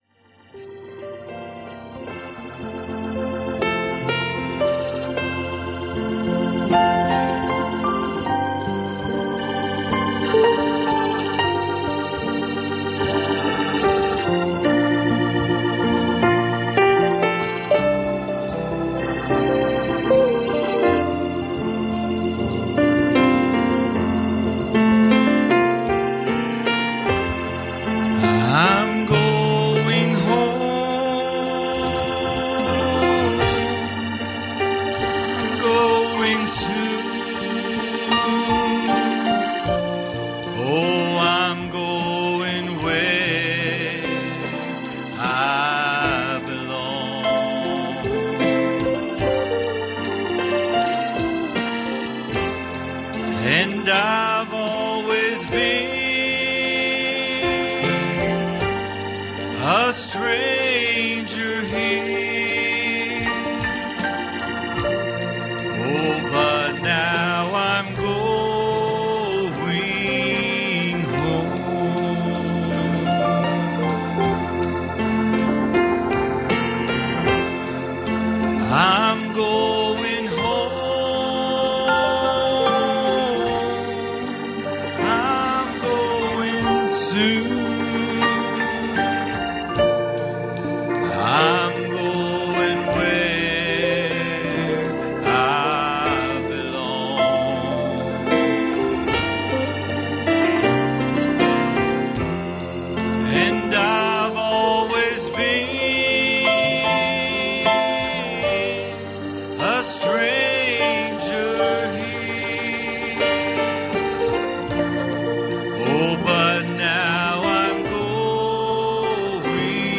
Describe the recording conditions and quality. Passage: Luke 10:30 Service Type: Wednesday Evening